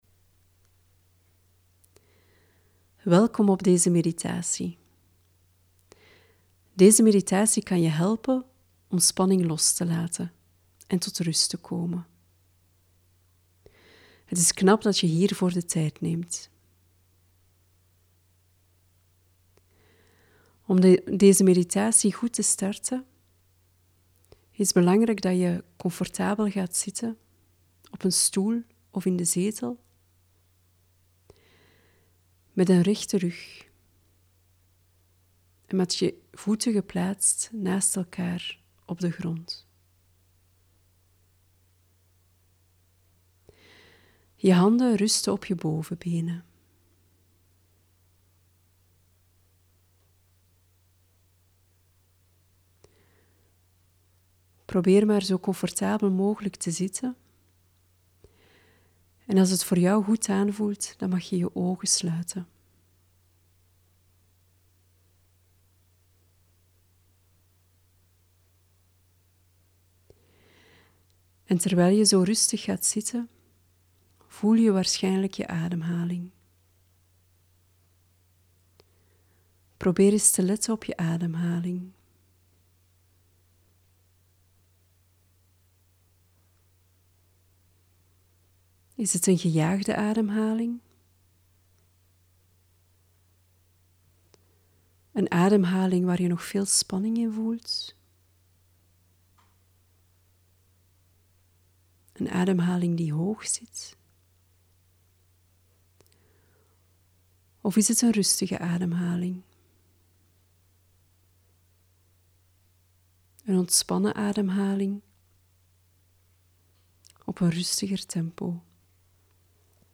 Opspannen en loslaten: een begeleide oefening om de spanning in je lichaam los te laten en je gedachten tot rust te laten komen